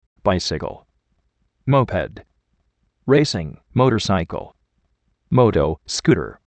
báisikl
móped